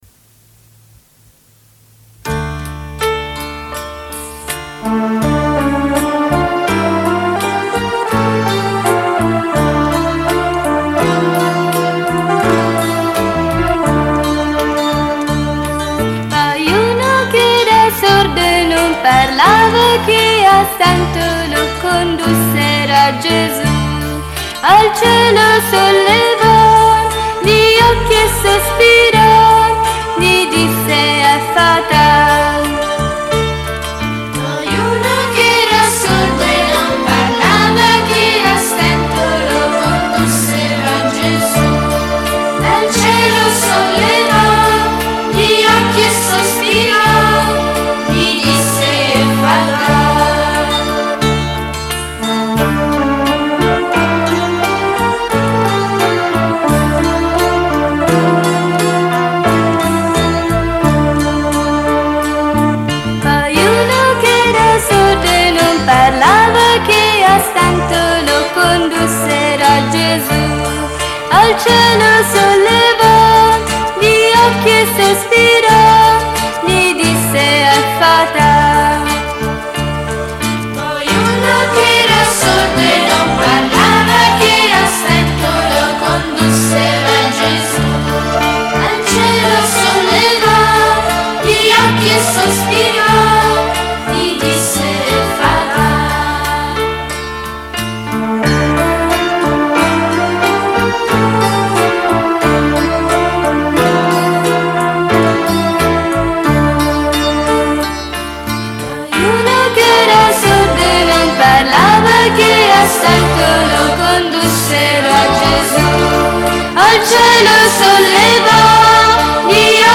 Canto per la Decina di Rosario e Parola di Dio: Poi uno che era sordo. Effatà!